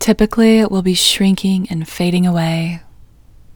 OUT Technique Female English 14